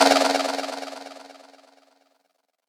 DDWV POP PERC 4.wav